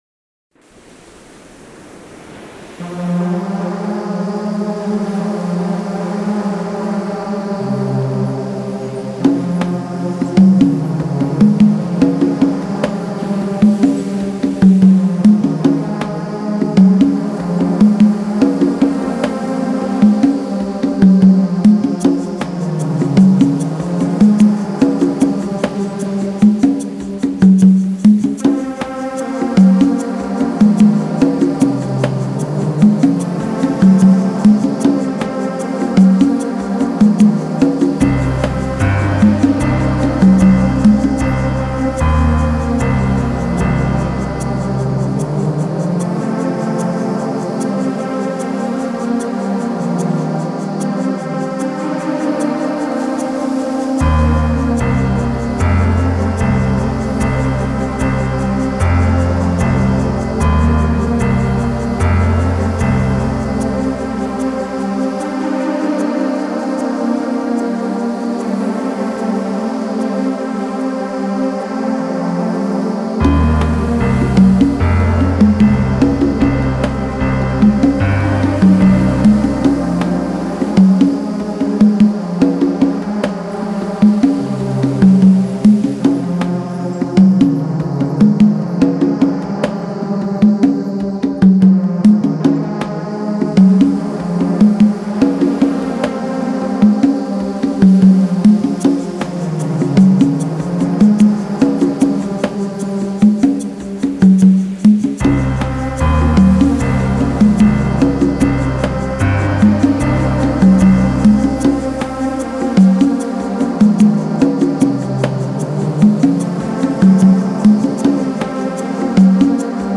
Genre: Relax, Meditation, Ambient, New Age, Ambient.